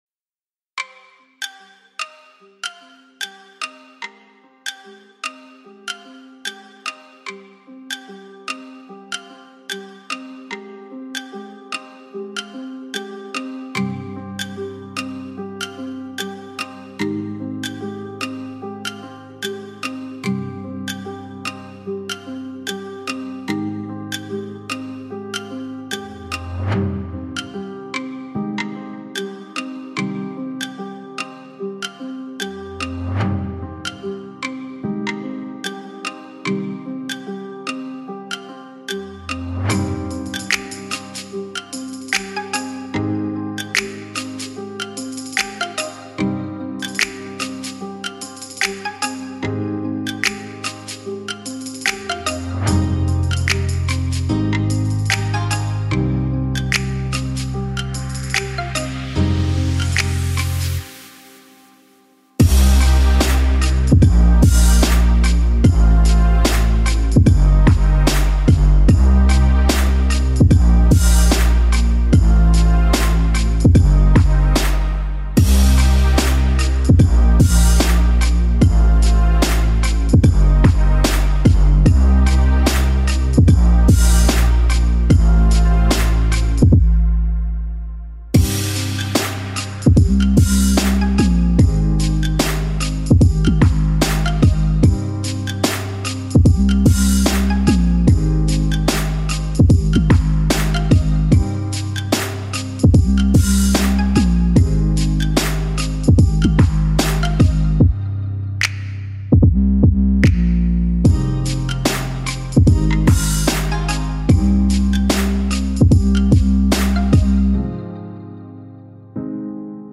Instrumentais